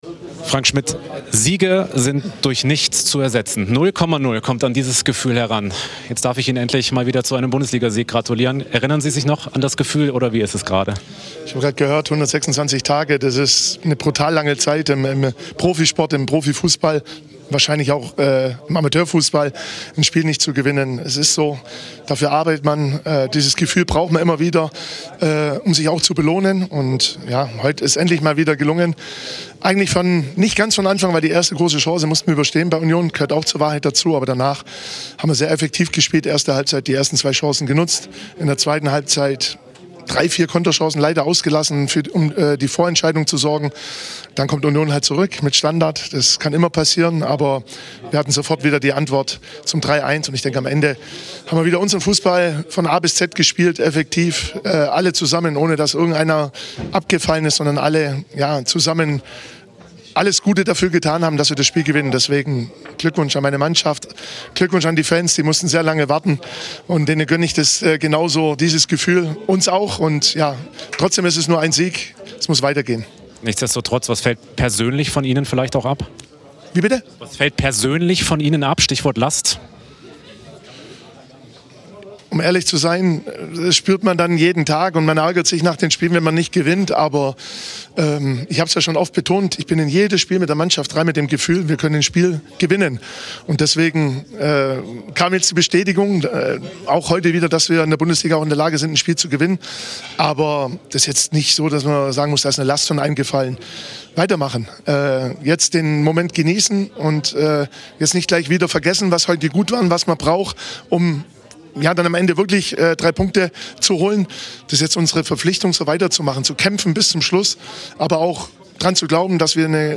Frank Schmidt, der Trainer des 1. FC Heidenheim, gratulierte nach dem Sieg gegen Union Berlin seinem Team und den Fans, die lange auf einen Erfolg warten mussten.